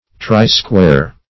Try-square \Try"-square`\, n.